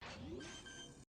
[SOUND] Spike Plant 2.ogg